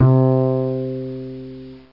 Bass Hi Sound Effect
bass-hi.mp3